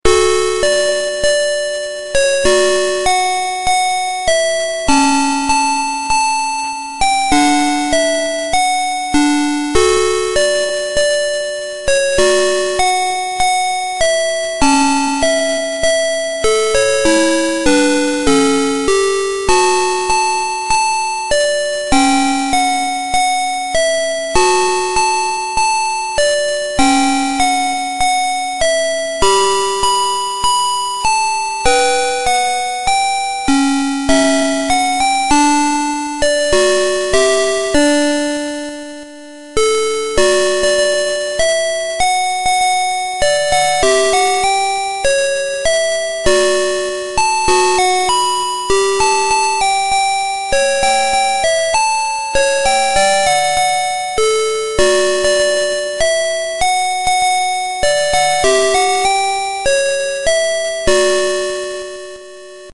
21 Christmas Songs Dual-Tong Melody
• Dual tone melody